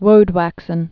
(wōdwăksən)